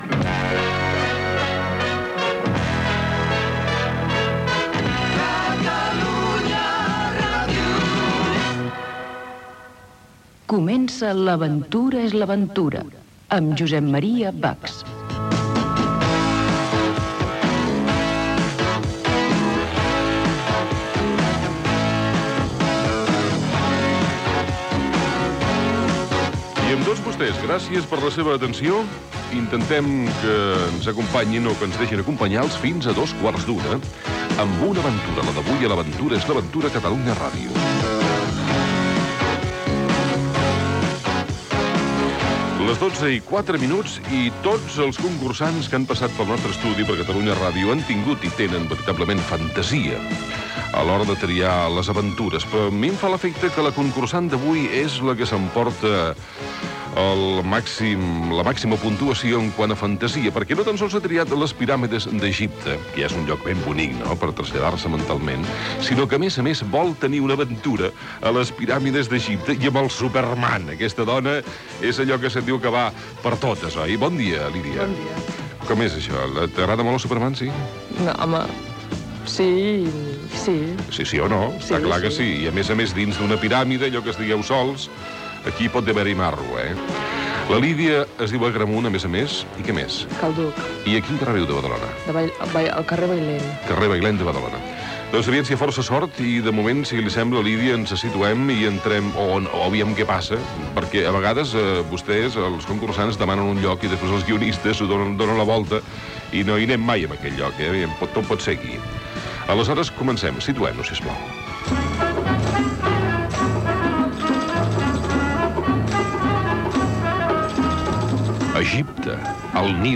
Primeres preguntes amb participació de l'audiència Gènere radiofònic Entreteniment